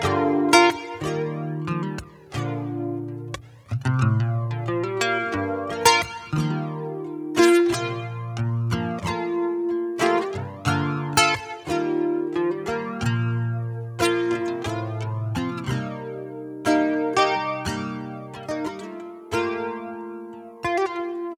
Flanger.wav
Klanglich äußert er sich durch resonante Schwebungen.